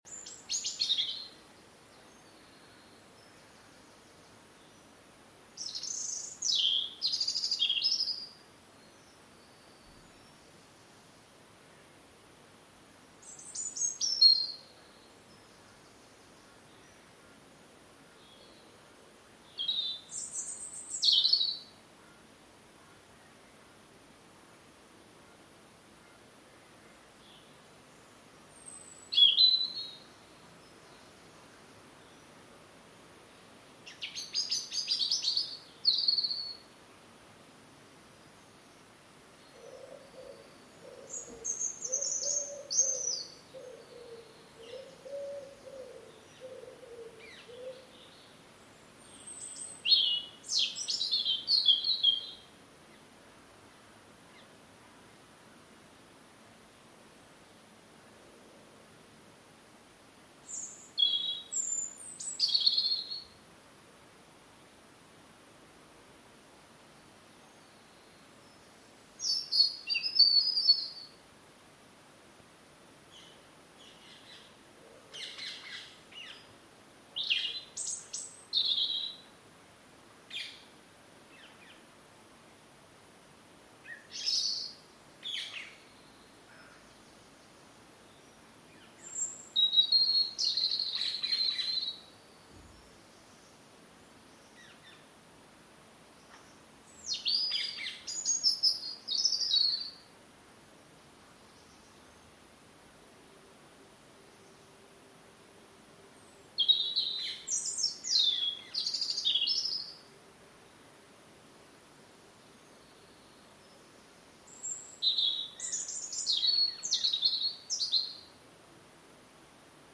Sunday birdsong before 9 AM 30 May 2021
Birdsong recorded from my bathroom window on Sunday morning